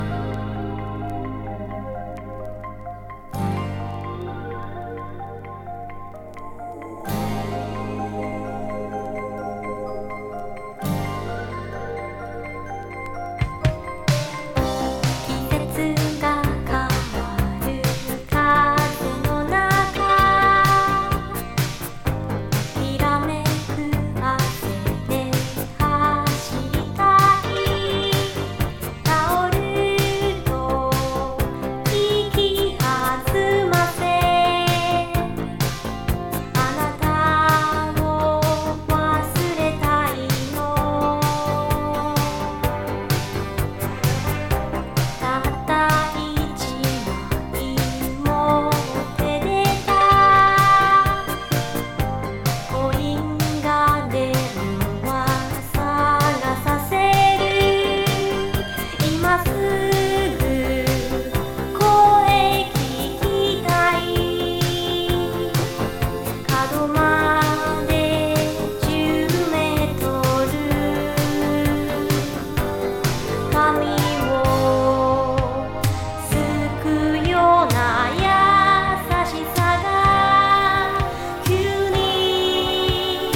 Japanese 和ソウル / ディスコ / ファンク レコード